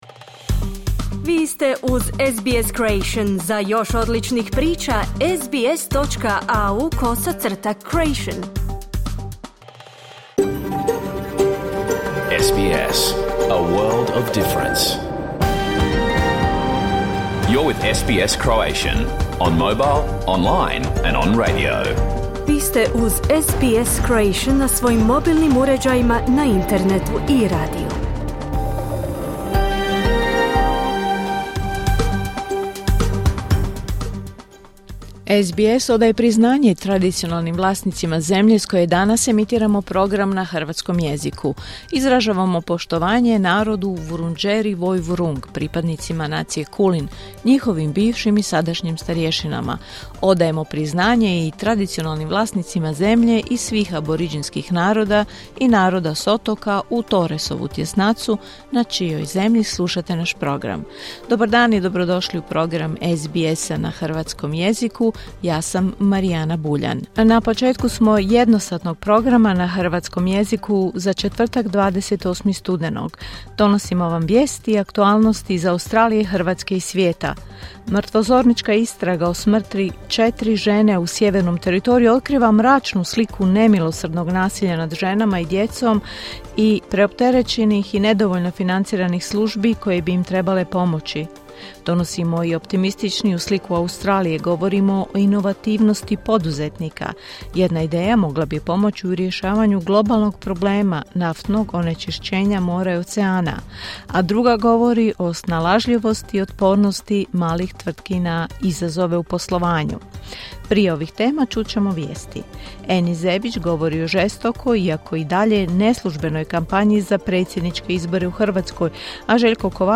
Vijesti i aktualne teme iz Australije, Hrvatske i ostatka svijeta. Emitirano uživo na radiju SBS1 u 11 sati po istočnoaustralskom vremenu.